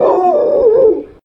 dog-howl-2.ogg